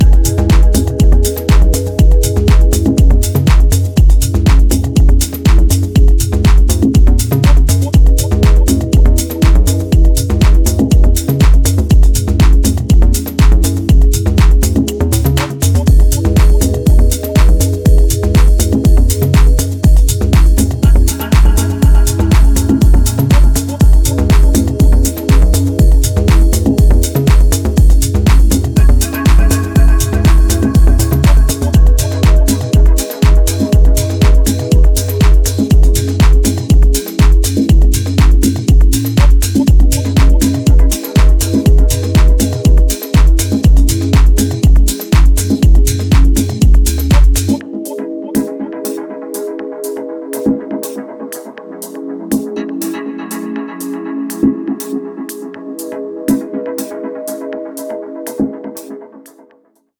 ハウスに流れるラテンの遺伝子をモダンなタッチで強調。